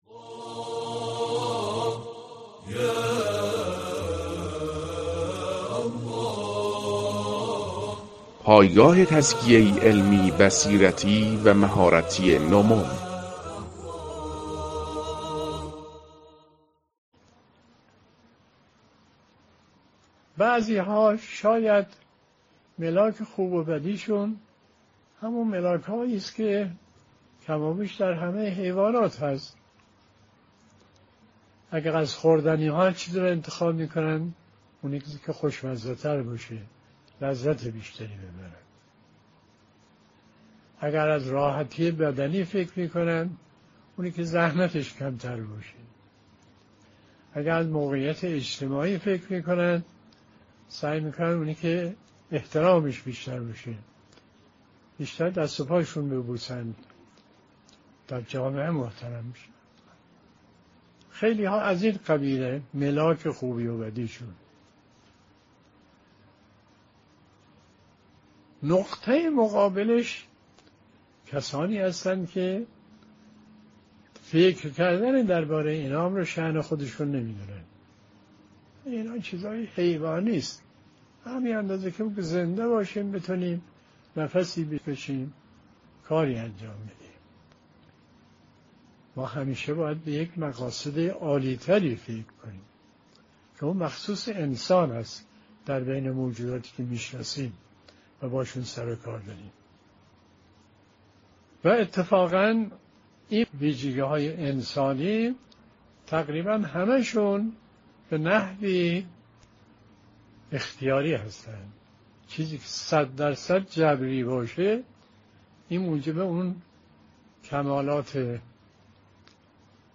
🔰 بیانات آیت الله مصباح یزدی؛ ملاک های متفاوت در انتخاب ها